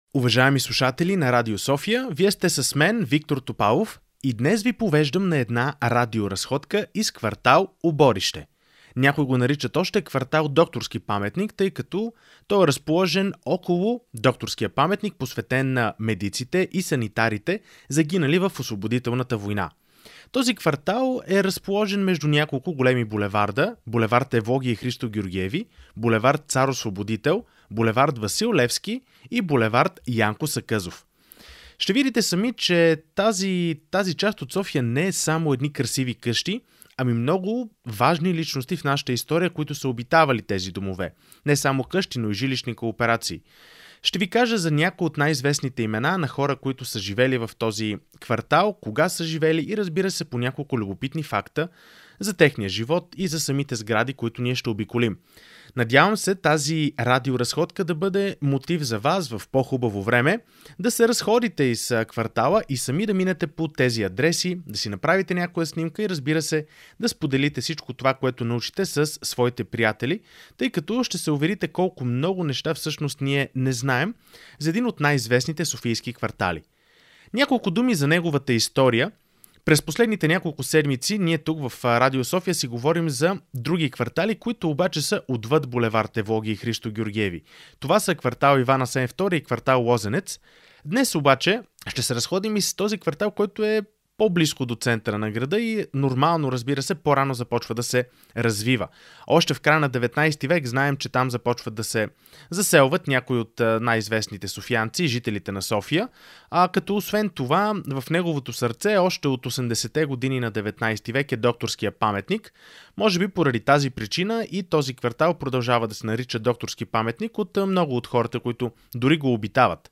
Радиоразходка из квартал Оборище, разположен около Докторския паметник – в Софийски разкази, част от Легендите на София